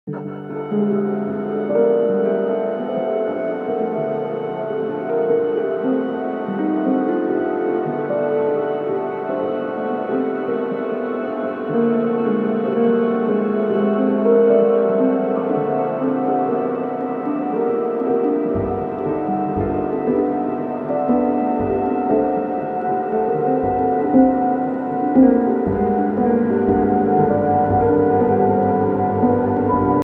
*nah, actually just all pieces played at once